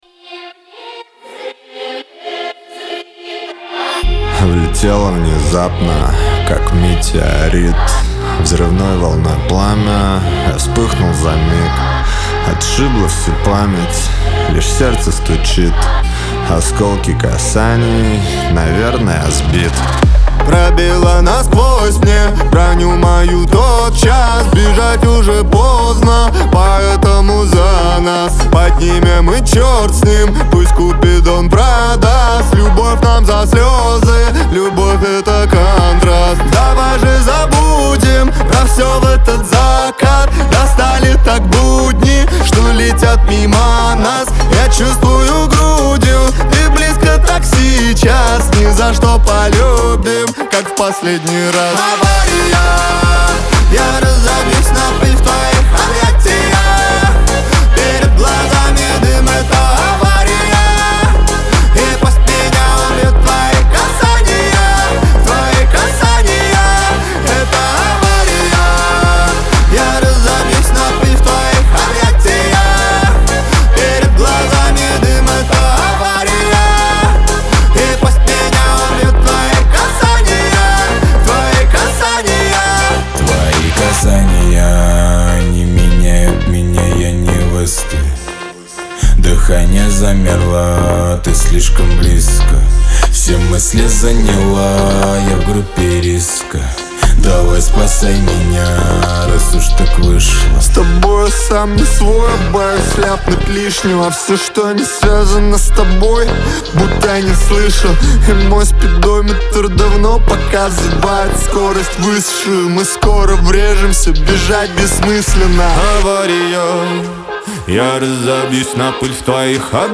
pop , эстрада
дуэт